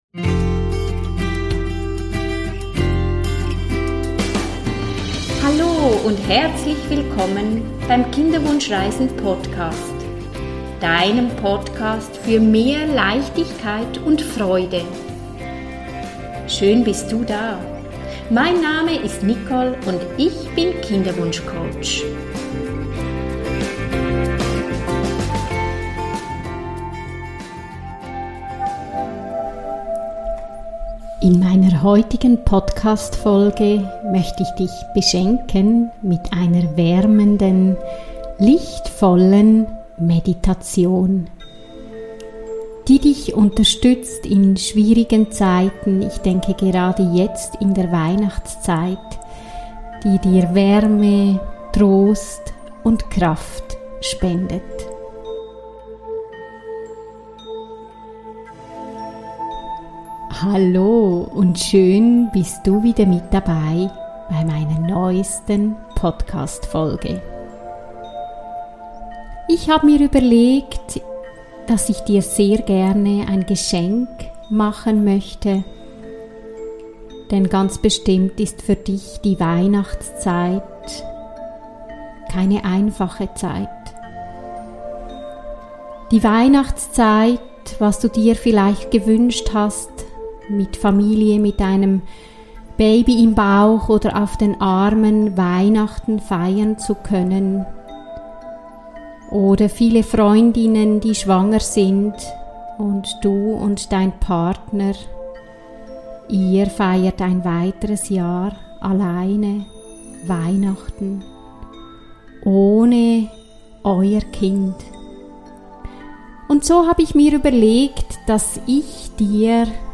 Beschreibung vor 1 Jahr In meiner heutigen Podcastfolge möchte ich dich beschenken mit einer Kerzen Meditation.